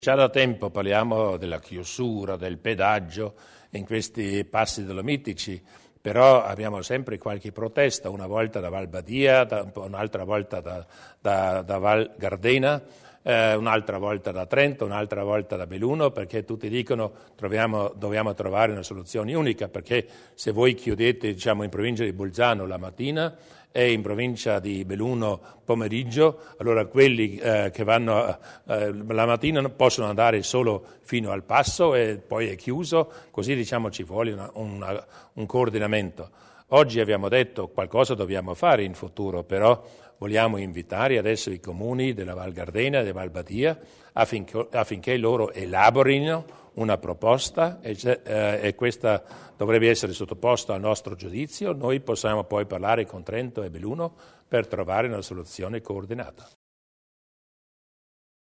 Il Presidente Durnwalder sul futuro dei passi dolomitici